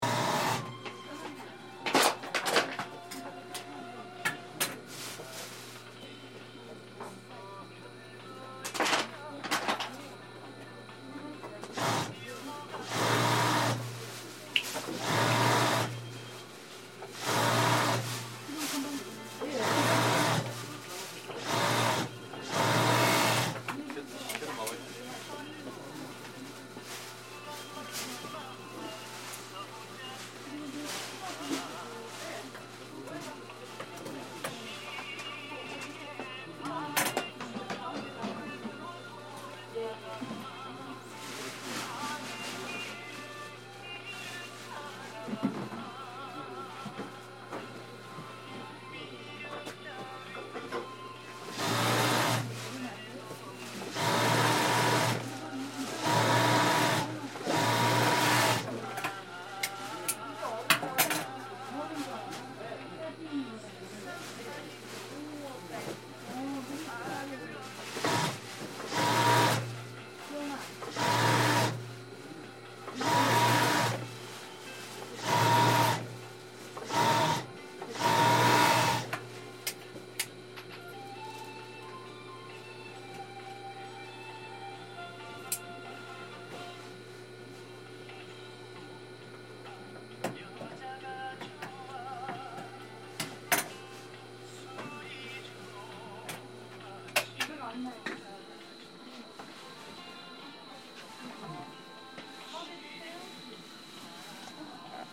Sewing machine in Gwangjang Market
Across town at the Gwangjang Market a tailor's sewing machine hums among the traditional Hanbok shops.
This is a sewing machine in Gwangjang Market.